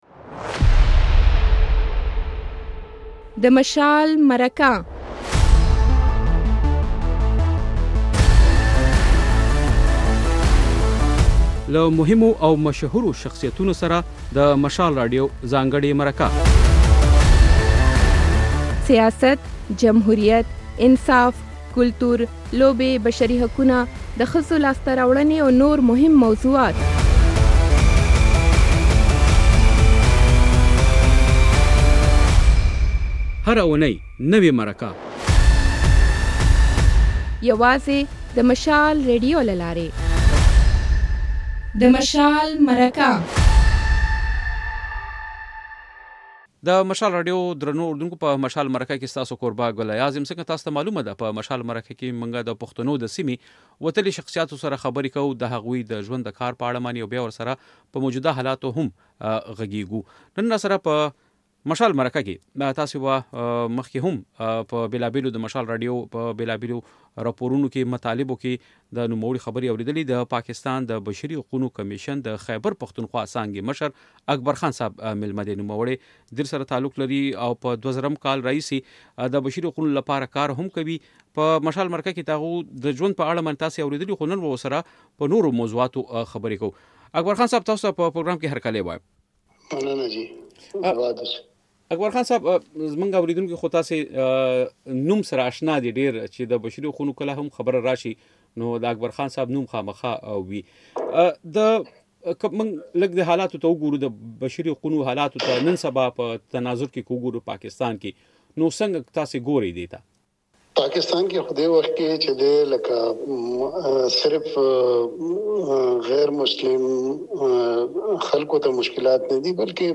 په مرکه کې مو له نوموړي سره په پاکستان کې د لږکیو لپاره د ملي کمېشن جوړولو لپاره د ډېسېمبر په لومړۍ اونۍ کې د منظور شوې قانوني مسودې پر ارزښت بحث کړی دی. بشپړه مرکه واورئ.